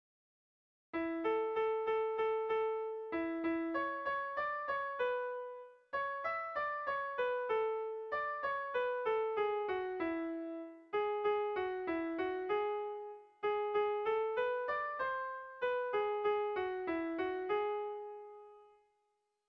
Erlijiozkoa
ABD..